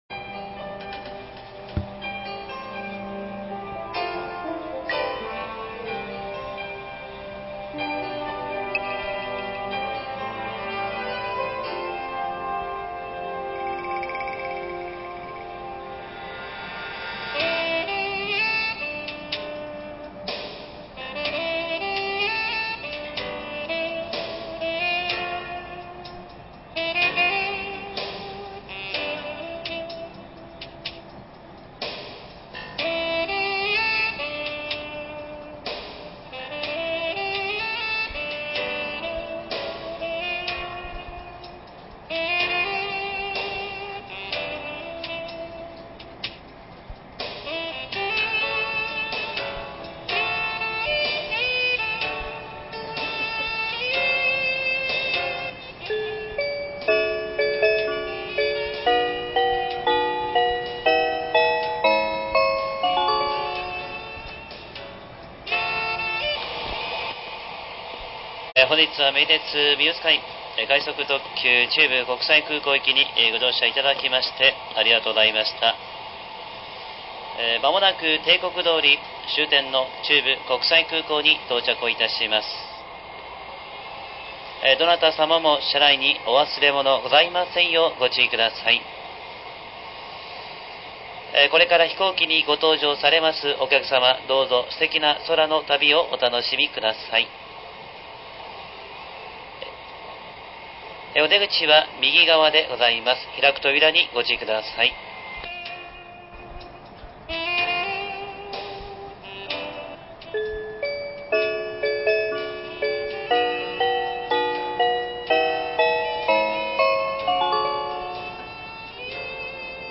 名鉄２０００系車内チャイム
空港到着前の車内放送（BGMは途切れています）